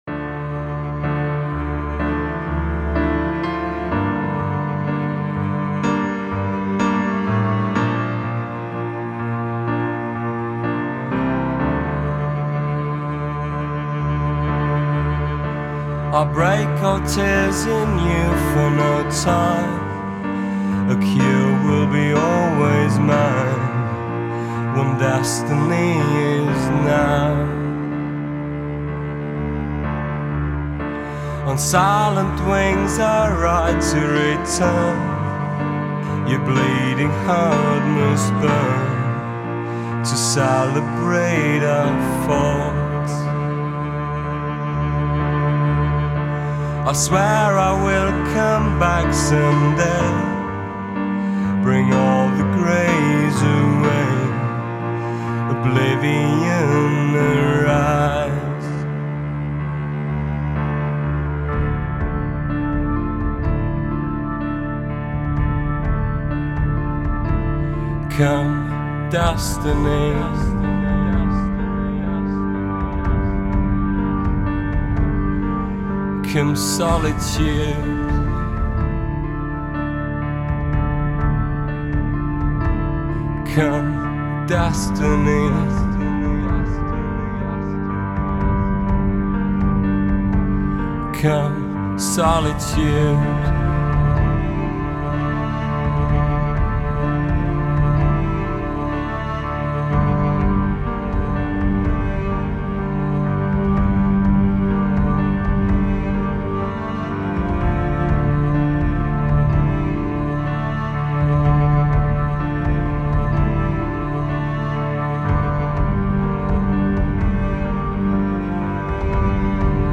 这就是来自德国的死亡/歌特/厄运金属乐团。